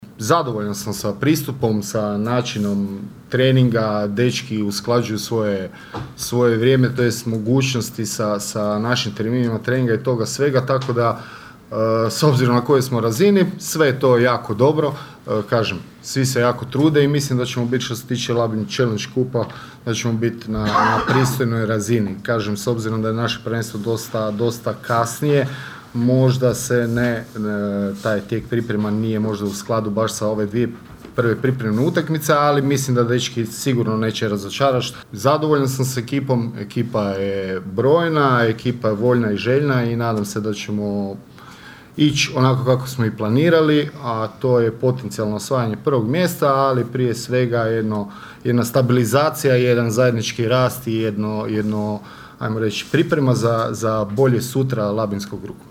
na konferenciji za novinare